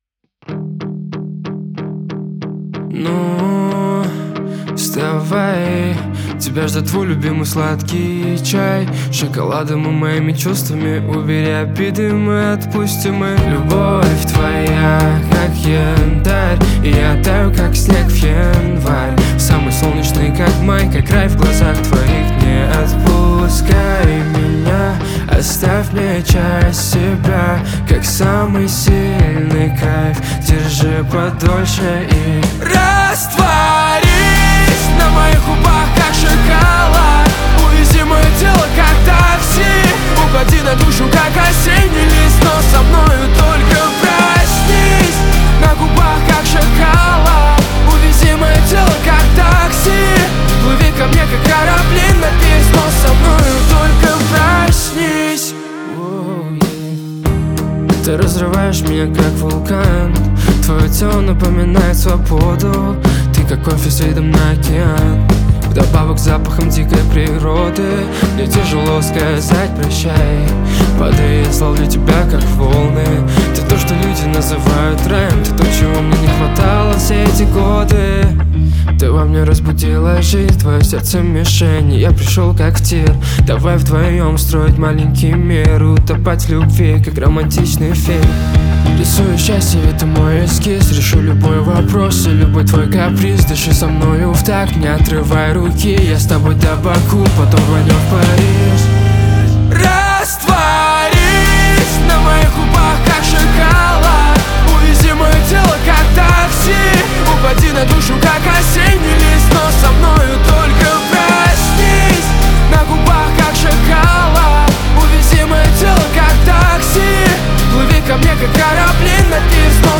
Джаз